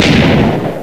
tnt_explode.ogg